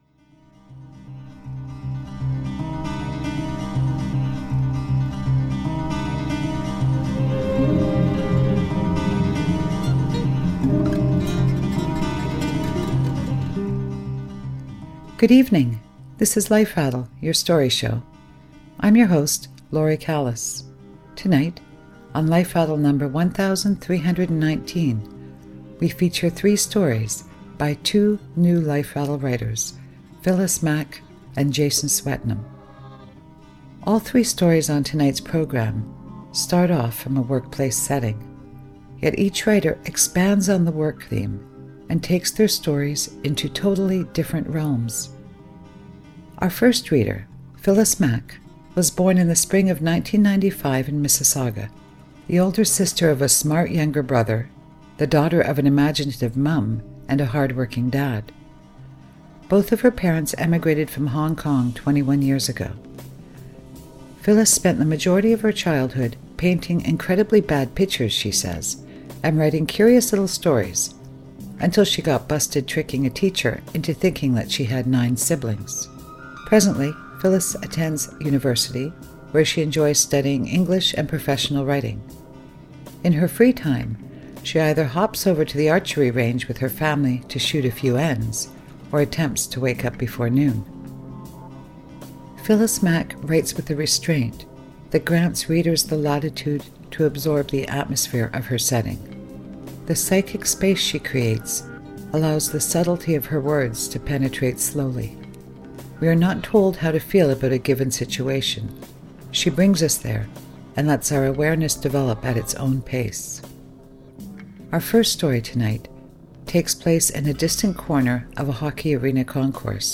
features three stories by two Life Rattle Writers